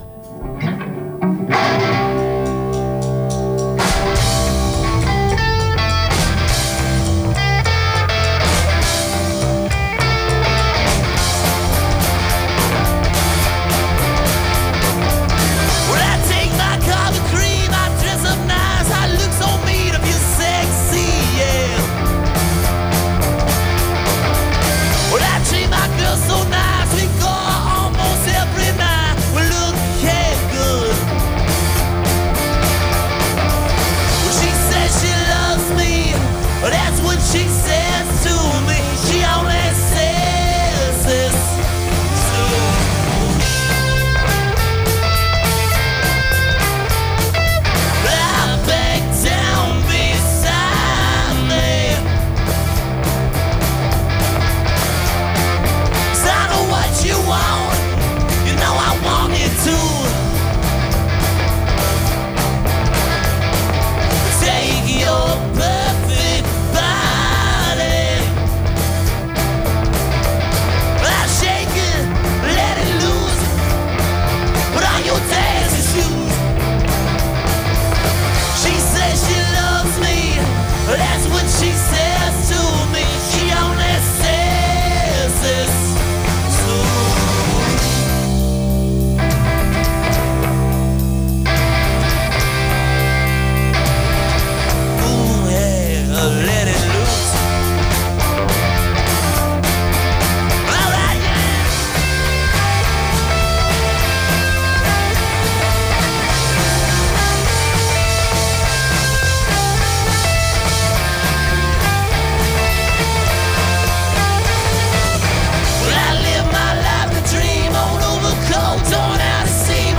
enregistrée le 17/03/2008  au Studio 105